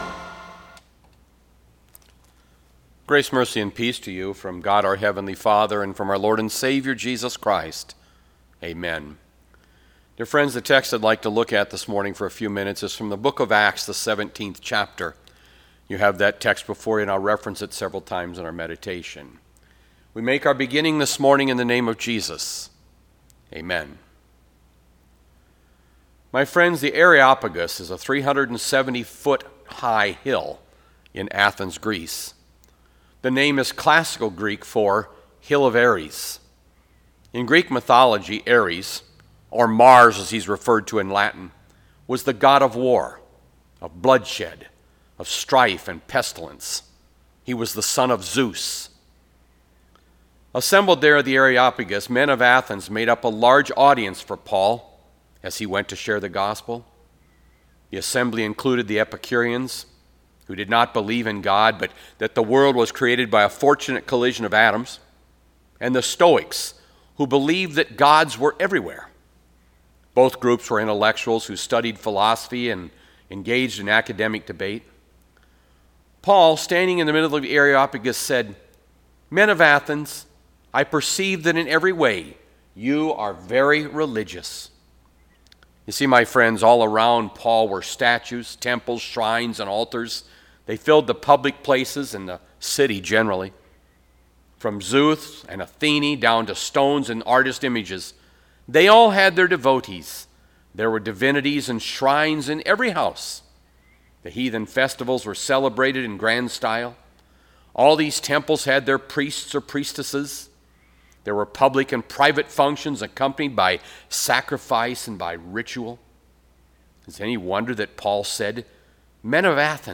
May 17, 2020  SERMON ARCHIVE